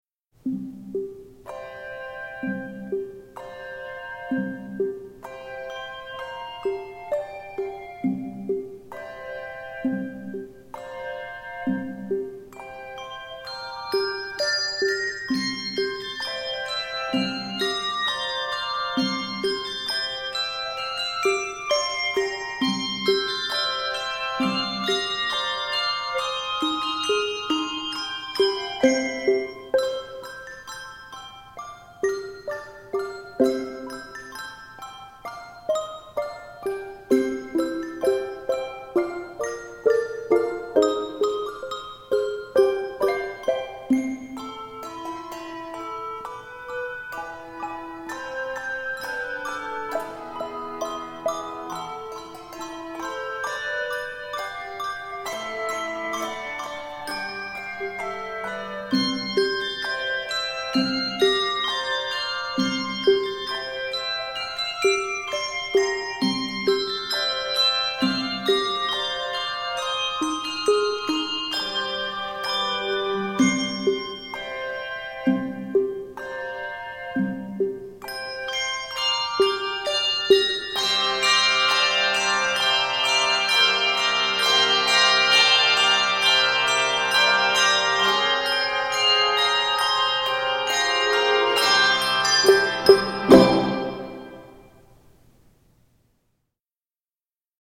Originally composed for a children's handbell choir